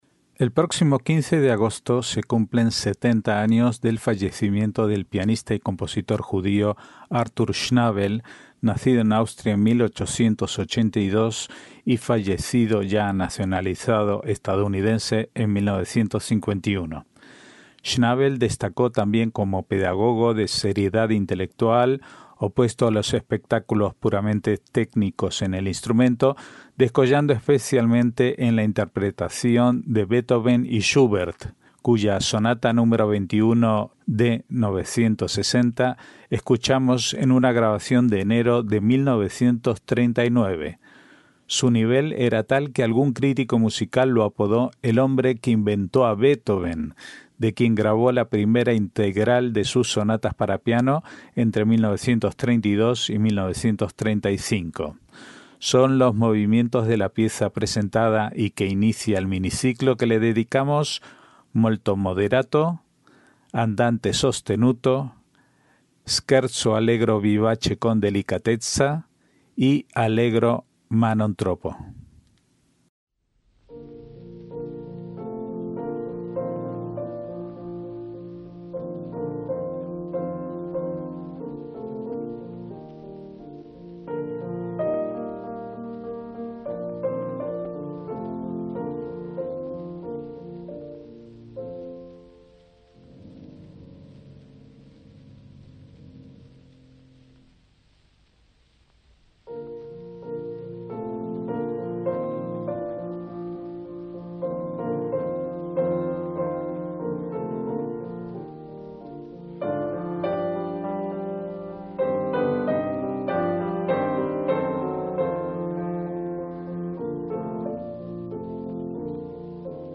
MÚSICA CLÁSICA
en una grabación de enero de 1939